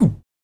unsatisfying-oomf.wav